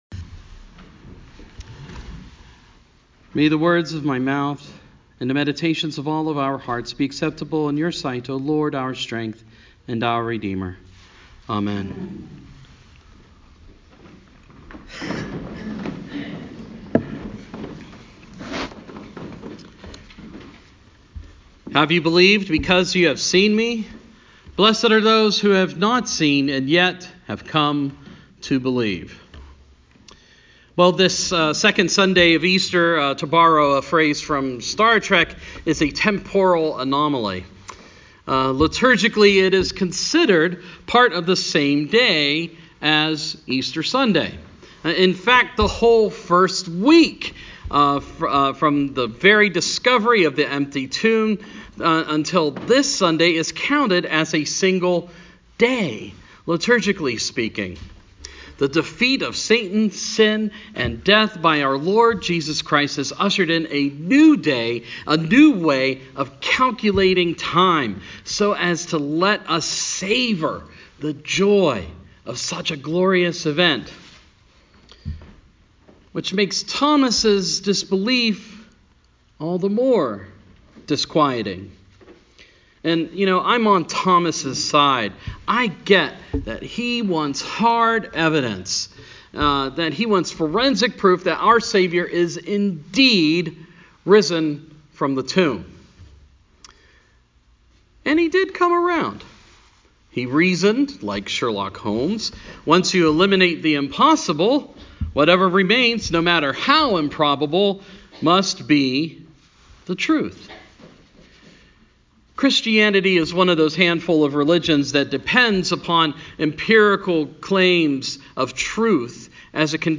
Sermon – Second Sunday in Easter